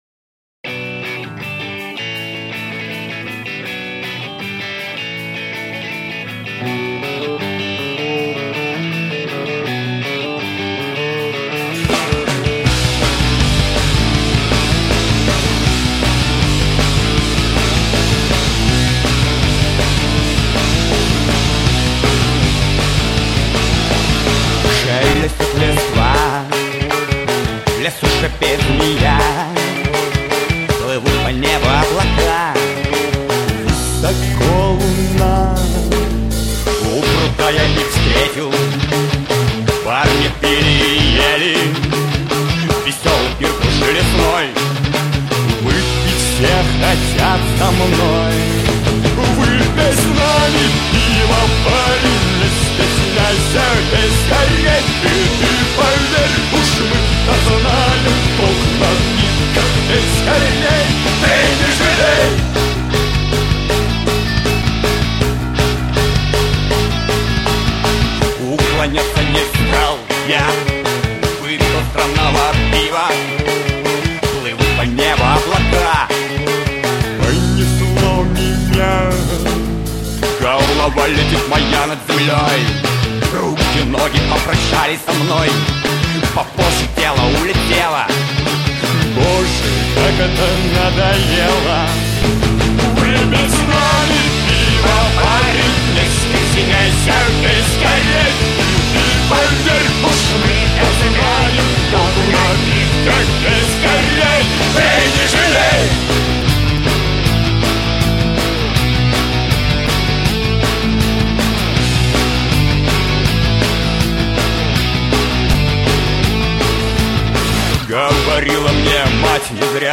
Главная » Онлайн Музыка » Рок